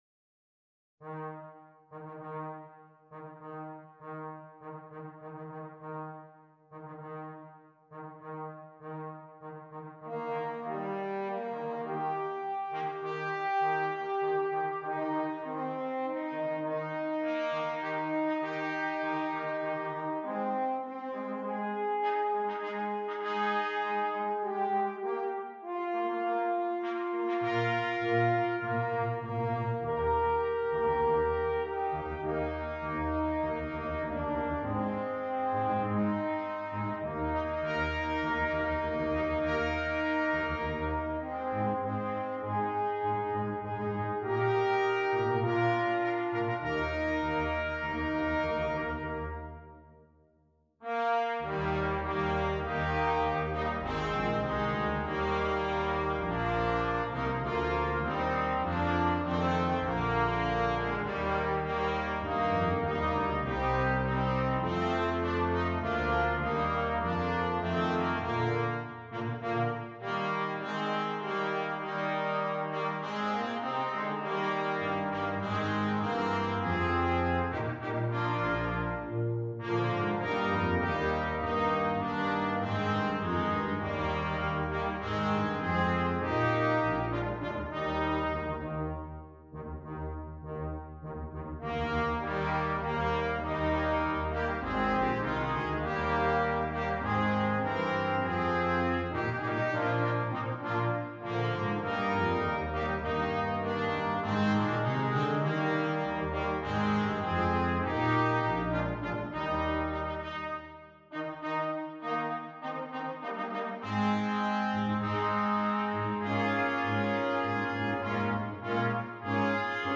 Brass Quintet and Optional Choir